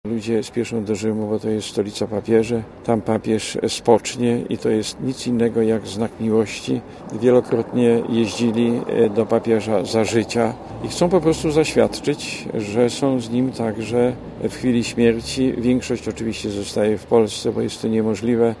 Mówi Henryk Muszyński